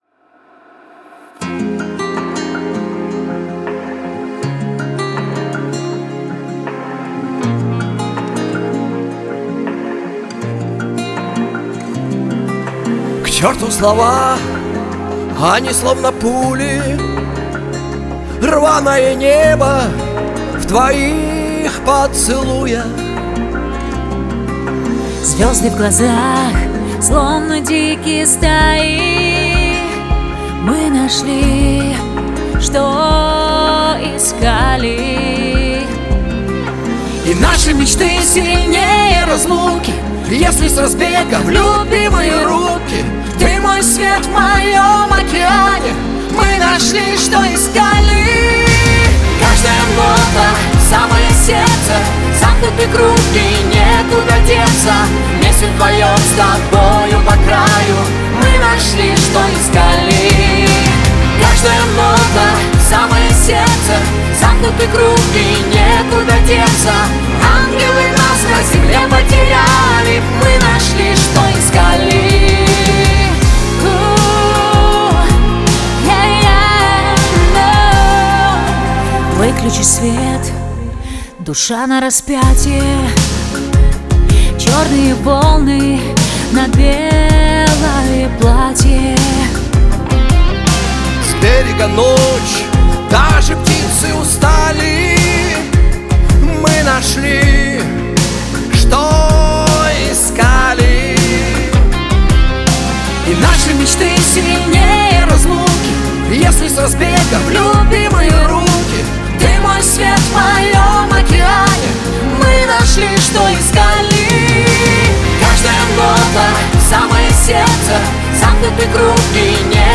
это яркая и эмоциональная композиция в жанре поп